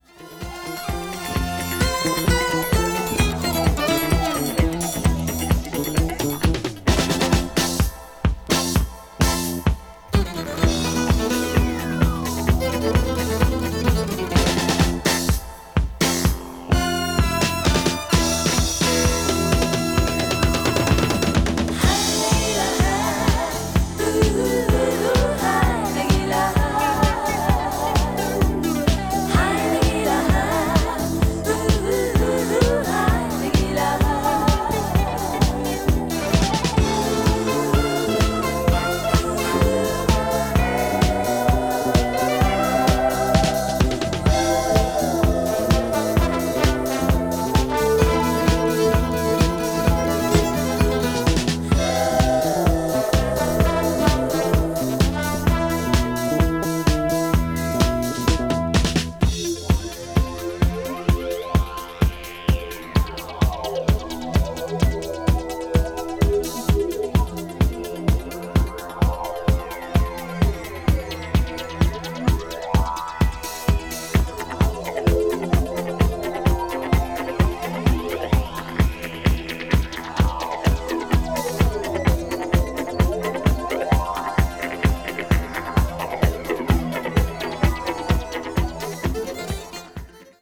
かなりド派手なアレンジによるアップリフティングなディスコを披露した作品。
boogie disco   disco relatad   italo disco   synth disco